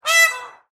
Peahen-call.mp3